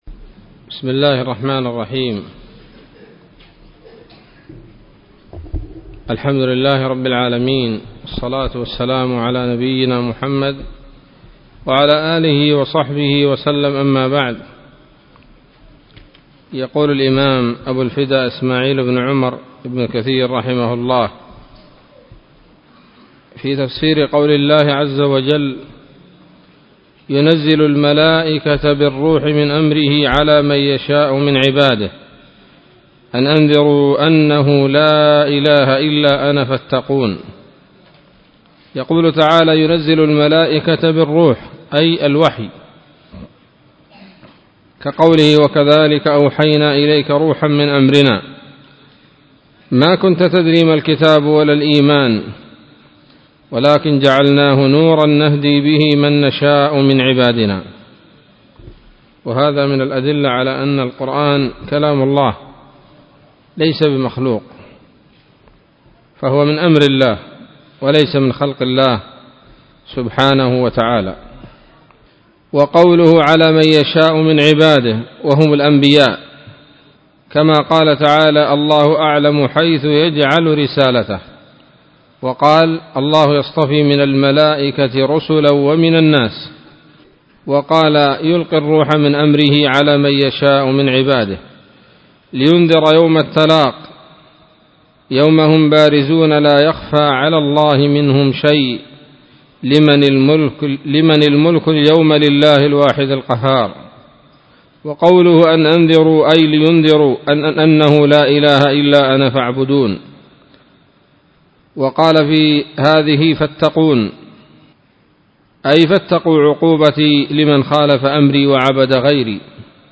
الدرس الثاني من سورة النحل من تفسير ابن كثير رحمه الله تعالى